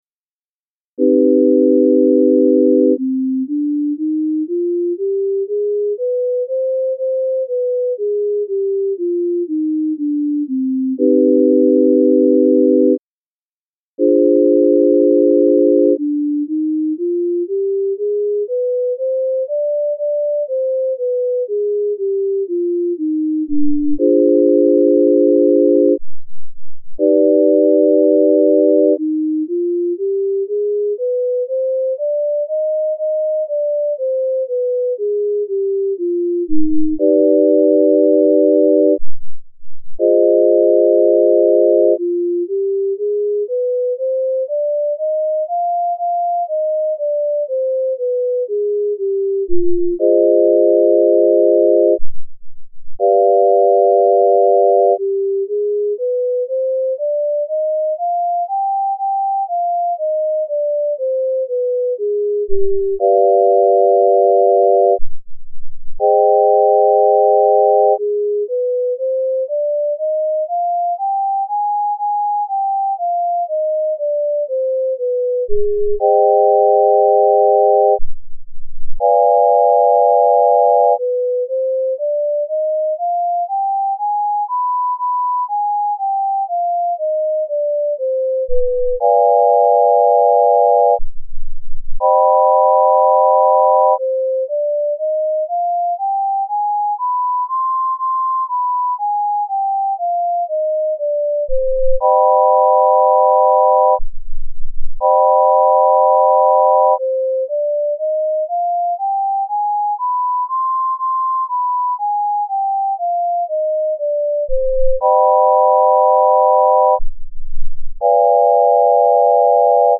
C-Harmonic Minor Scale Using the Pythagorean Scale
music02_009_Harmonic_Minor_C_Pythag.mp3